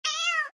Звуки котов
Звук кошачьих когтей, царапающих поверхность